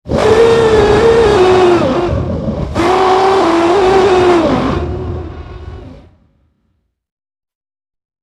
Sound Effects
Sea Serpent Roar Deep Long Guttural Elephant Roar